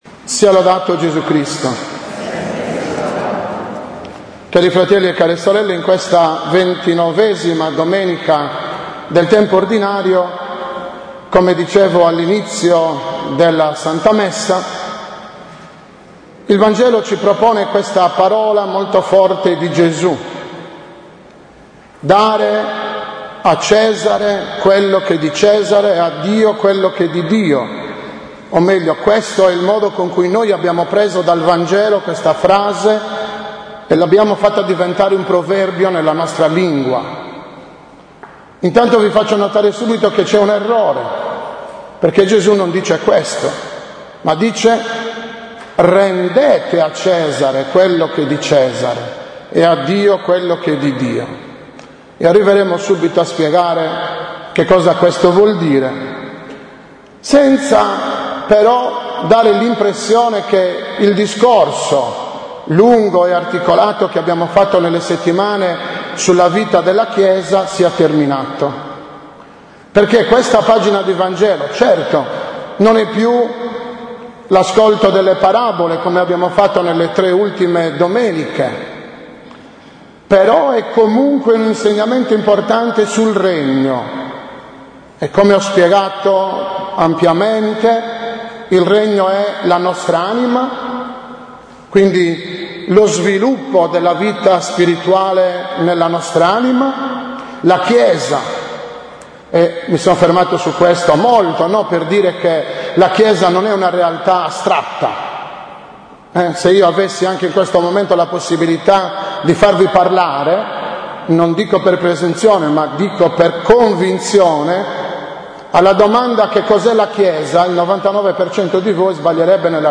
19.10.2014 – OMELIA DELLA XXIX DOMENICA DEL TEMPO ORDINARIO